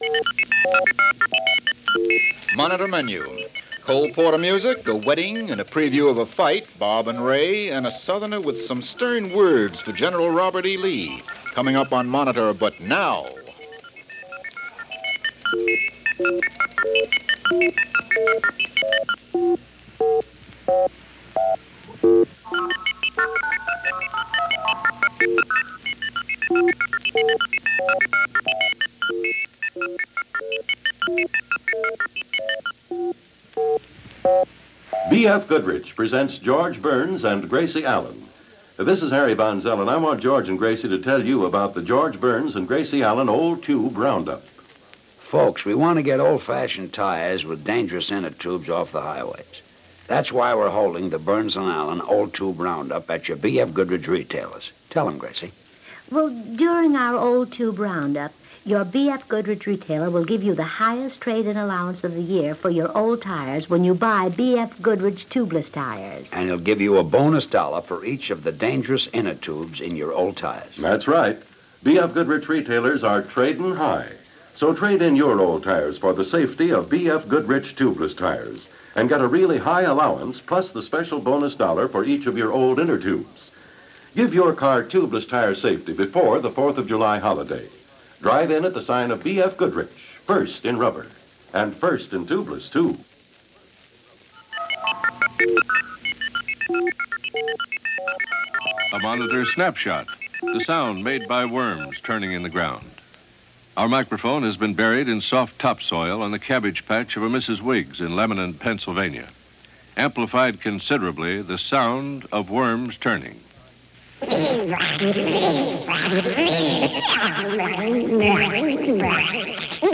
A Sunday Afternoon in 1955 - An hour of NBC Radio's Monitor, as it was broadcast on June 19, 1955 - Past Daily Weekend Pop Chronicles.